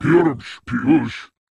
Jiralhanae voice clip from Halo: Reach.
Category:Covenant speech